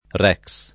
rex [lat. r $ k S ] s. m.